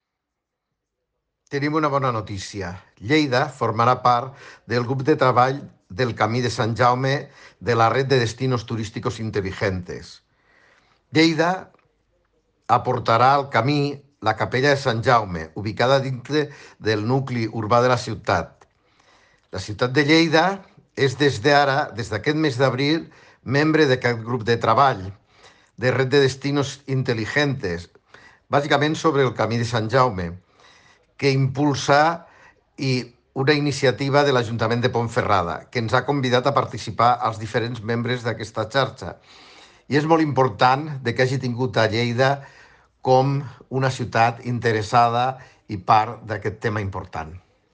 Tall de veu del tinent d'alcalde, Paco Cerdà, sobre Lleida, que s'integra al grup de treball sobre el Camí de Sant Jaume de la Red de Destinos Turísticos Inteligentes